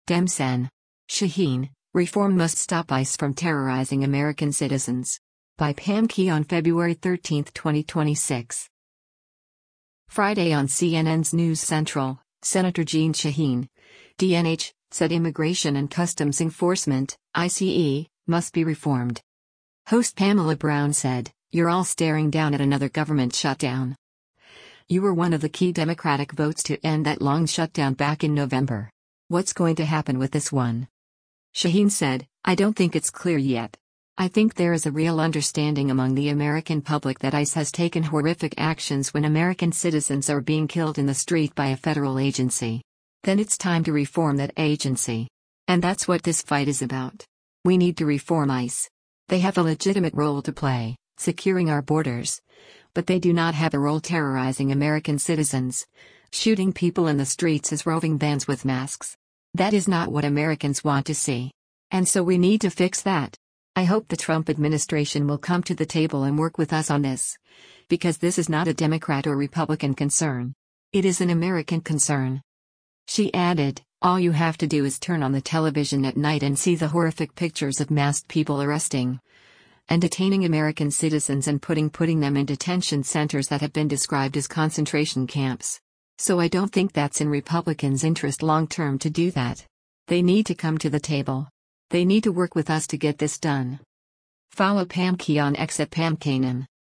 Friday on CNN’s “News Central,” Sen. Jeanne Shaheen (D-NH) said Immigration and Customs Enforcement (ICE) must be reformed.